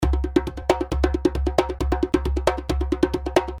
Djembe loops - 135 bpm (27 variations)
Real djembe loops playing at 135 bpm.
The Djembe loops are already in mix mode, with light compression and EQ.
The Djembe was recorded using vintage neumann u87 as main microphone, And 2 451 AKG microphones for the stereo ambient sound. The loops are dry with no effect , giving you freedom, adding the right effect to your project. Djembe is west african drum but the loops here are more ethnic, arabic and brazilian style.